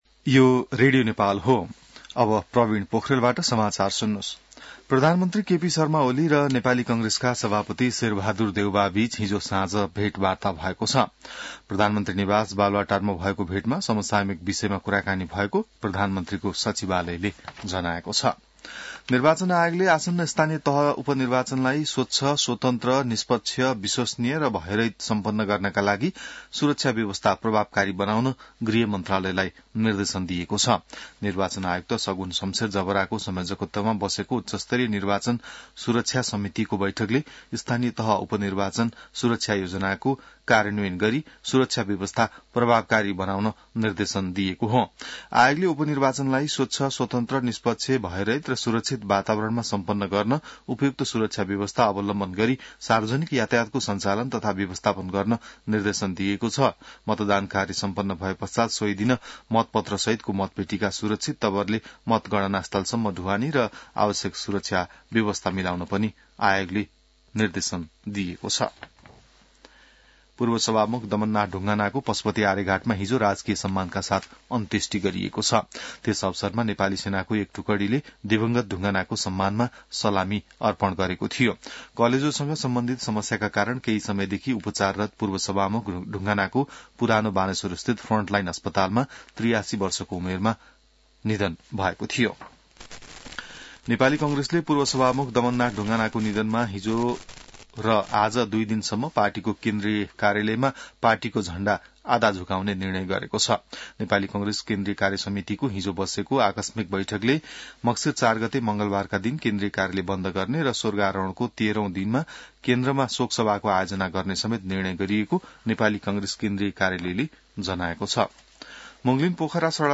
बिहान ६ बजेको नेपाली समाचार : ५ मंसिर , २०८१